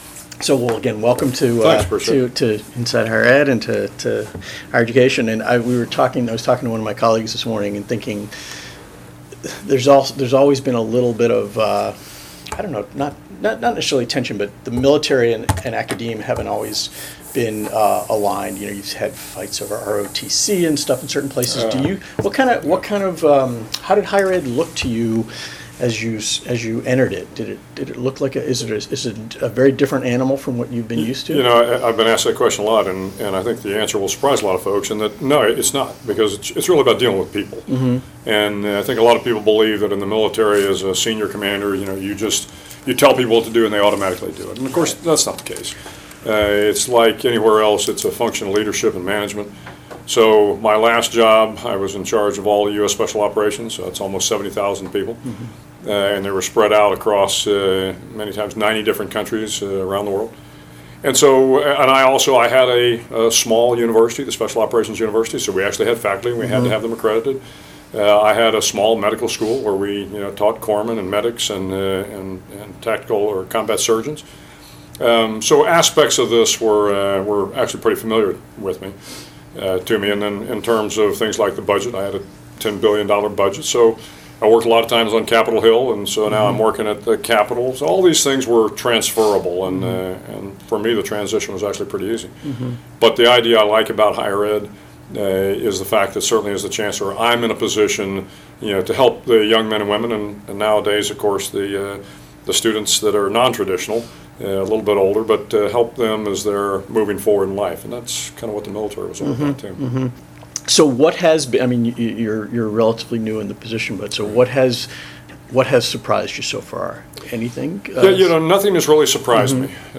In a podcast interview, William H. McRaven, the new chancellor of the University of Texas System, discusses the transition from the military to higher ed, the roles of a system and chancellor, and "chain of command."